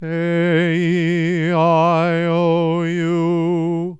A E I O U Sung in the middle range:
aeiuoMid.wav